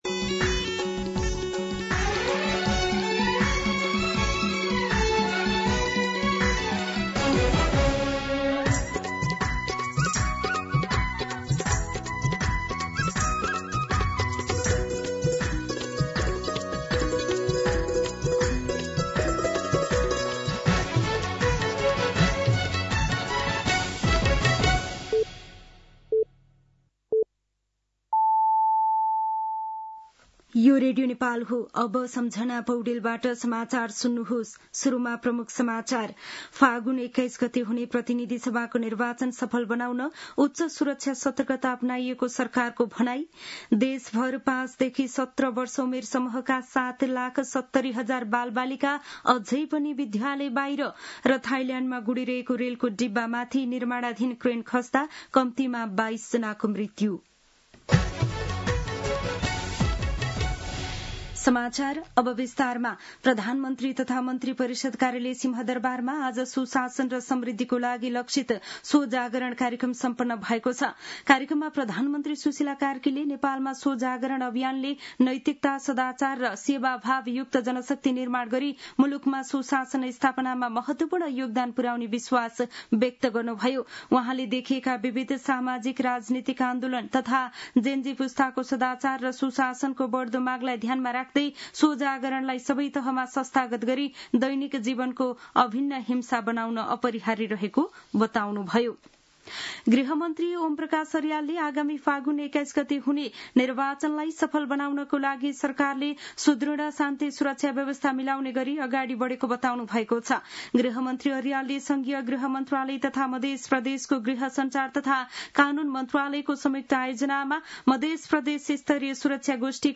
दिउँसो ३ बजेको नेपाली समाचार : ३० पुष , २०८२
3-pm-Nepali-News-3.mp3